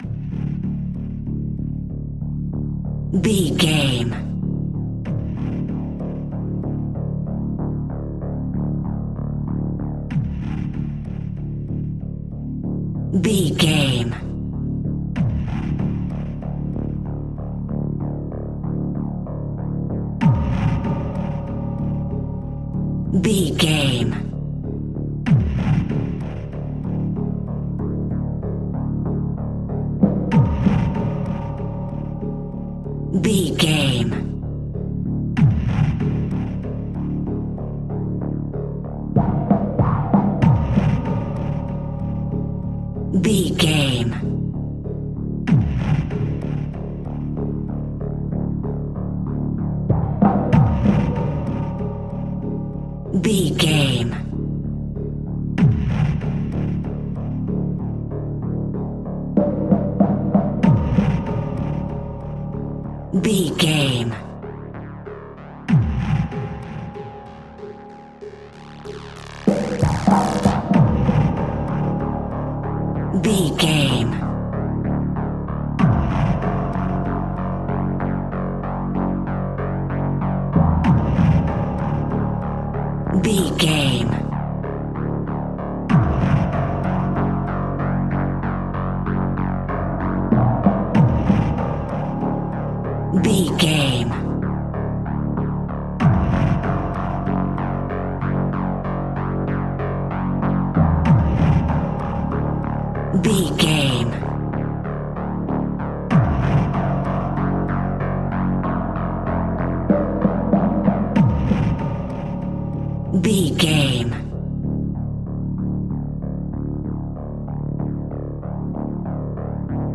Piano Nightmare Music Cue.
In-crescendo
Thriller
Aeolian/Minor
D
scary
ominous
dark
haunting
eerie
bass guitar
synthesiser
horror piano